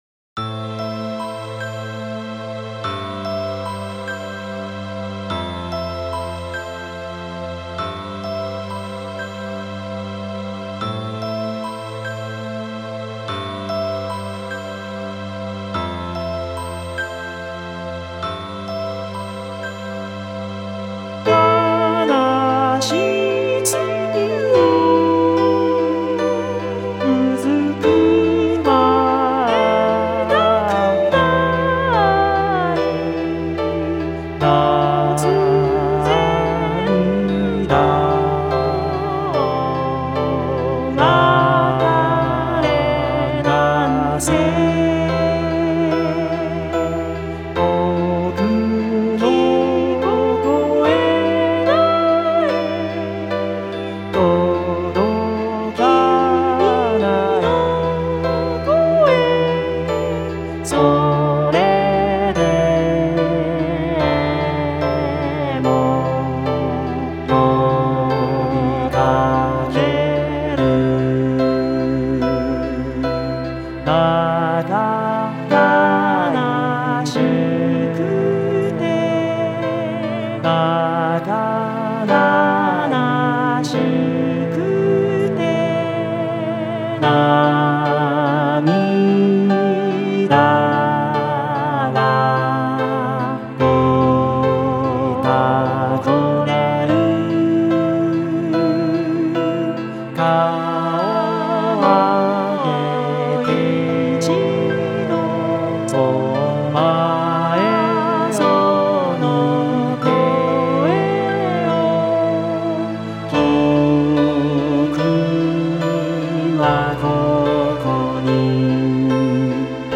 歌もの（ＶＯＣＡＬＯＩＤを使用したもの）
ところどころ同じ歌詞、音程。